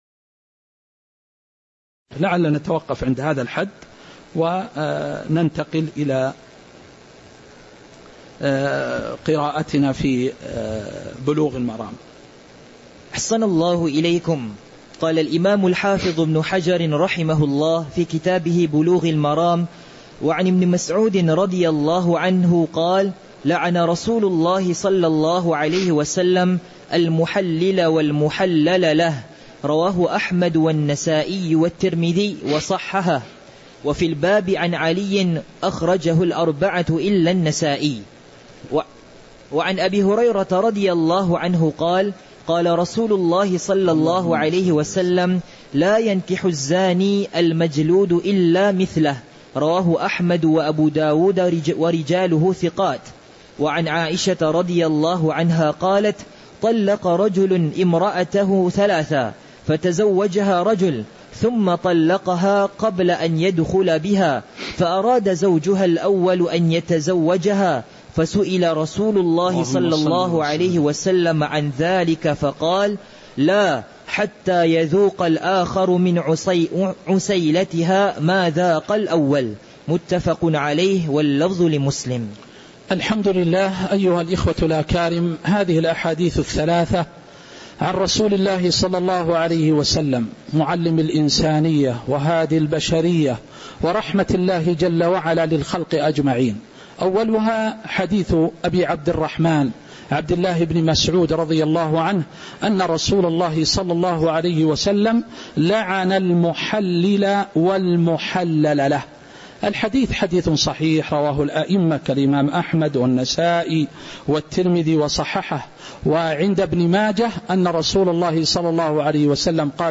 تاريخ النشر ٢٣ شعبان ١٤٤٦ هـ المكان: المسجد النبوي الشيخ